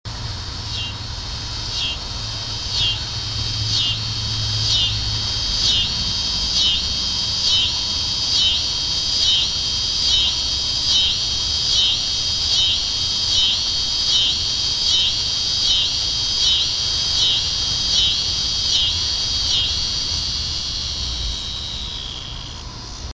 Cicada Sounds
Nothing I found sounded like the cicadas around here so I recorded one myself. This fellow was so loud that it came through pretty well even from a neighbor's tree. I like how he sounds as he winds down -- reminiscent of an engine winding down. These guys are deafening.
Cicada Sound (mp3)
Cicada_Huntsville.mp3